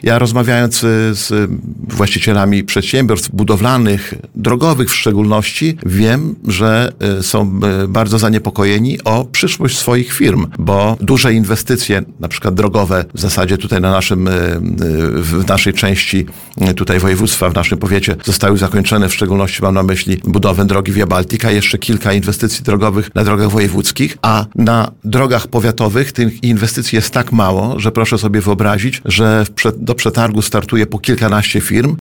Starosta łomżyński Lech Szabłowski przyznał na antenie Radia Nadzieja, że samorządom z regionu coraz trudniej otrzymywać rządowe dofinansowanie.